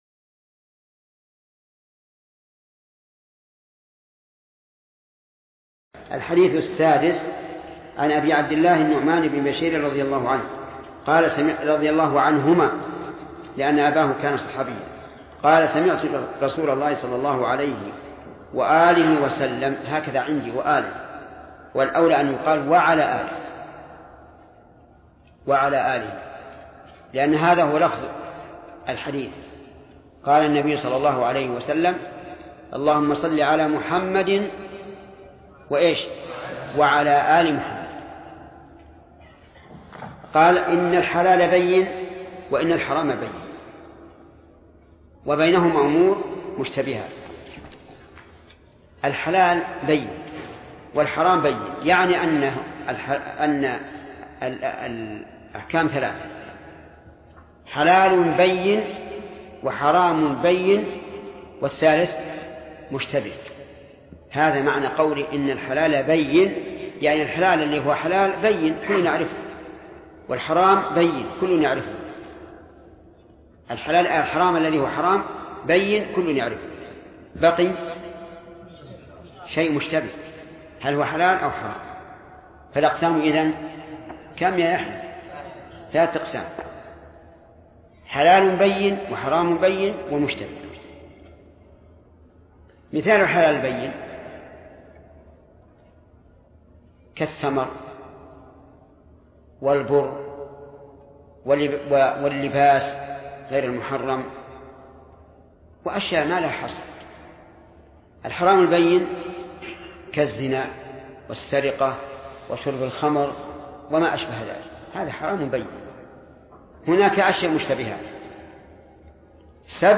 الدرس التاسع: من قوله: الحديث السادس، إلى: نهاية شرح الحديث السادس.